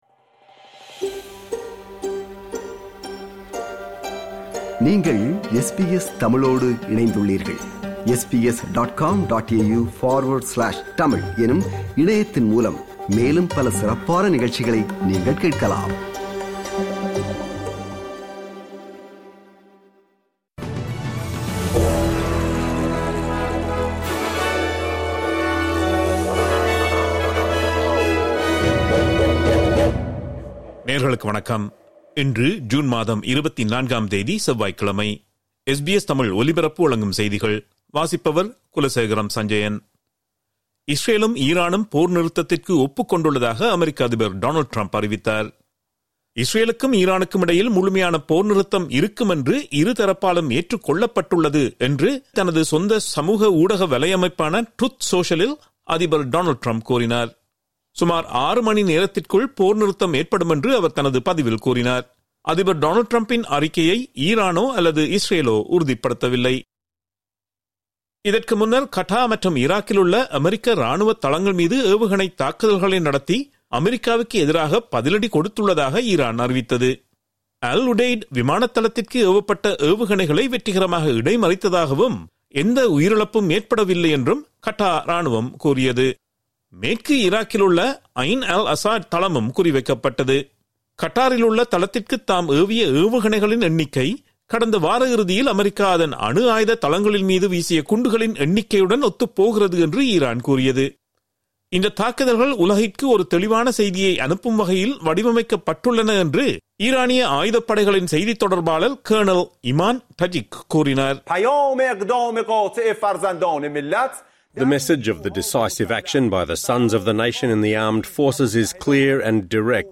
SBS தமிழ் ஒலிபரப்பின் இன்றைய (செவ்வாய்க்கிழமை 24/06/2025) செய்திகள்.